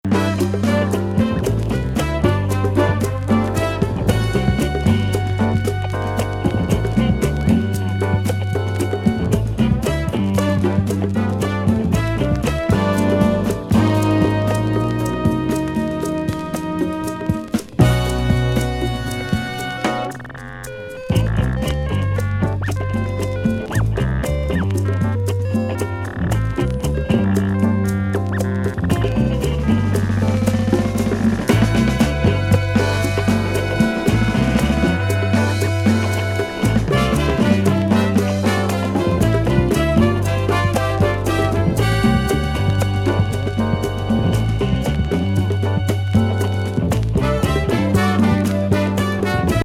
ファンキー&グルーヴィーなブラス・ロック名盤!!